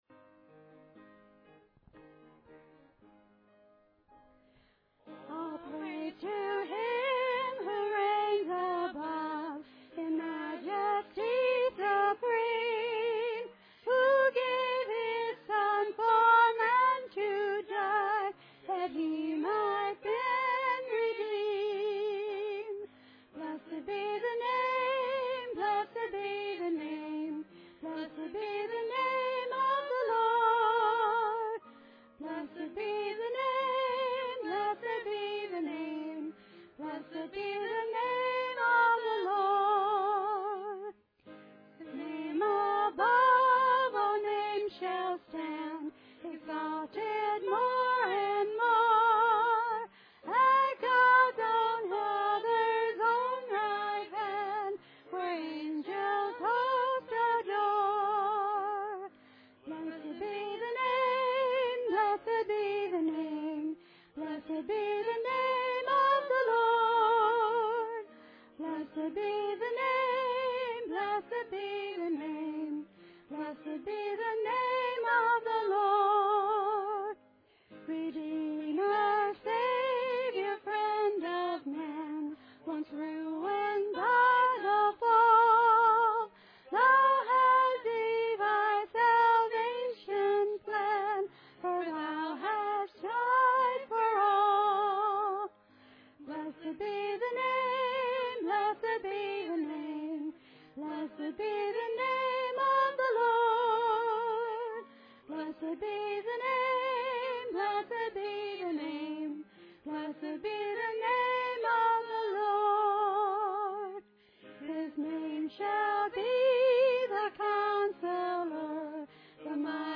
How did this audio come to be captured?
Series: Philippians Passage: Philippians Service Type: Sunday Service Topics